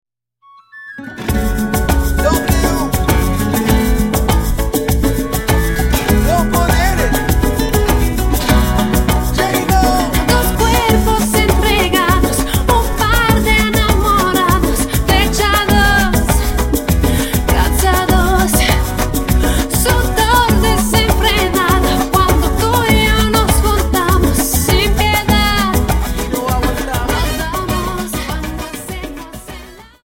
Dance: Samba 50